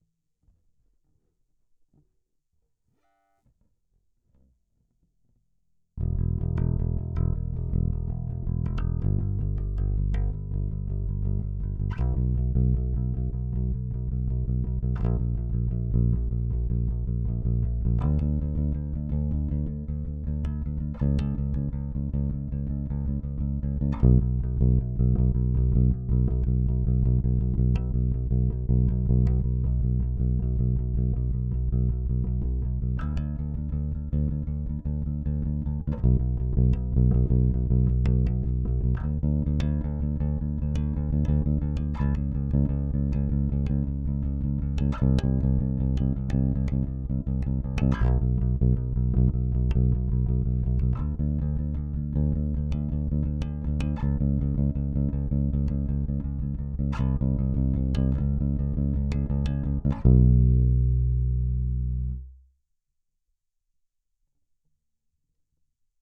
Bass Preamp.L.wav